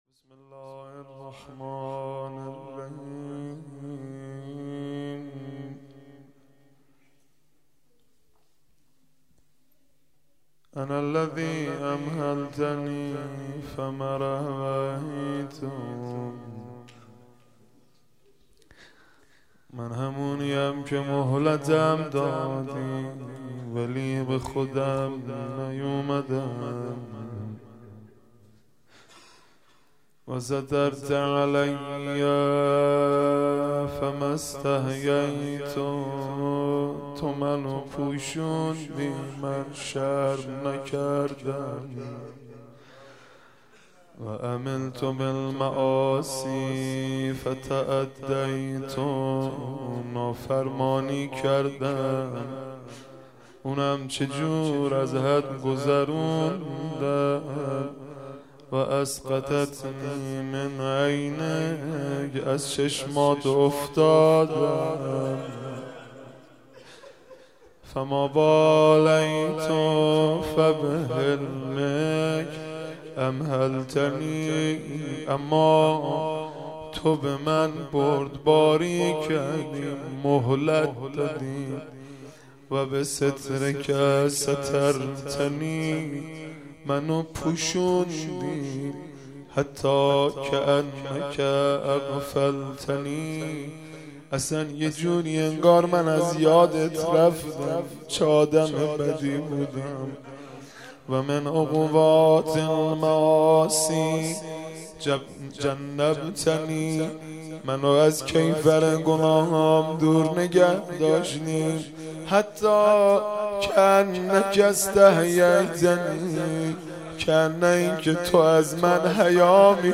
مناجات خوانی و مداحی حاج مهدی رسولی در شب بیست و چهارم ماه رمضان
به مناسبت ماه مبارک رمضان، مناجات خوانی و مداحی زیبا و دلنشین از حاج مهدی رسولی در شب بیست و چهارم ماه رمضان را با روزه داران و میهمانان سفره الهی به اشتراک می گذاریم.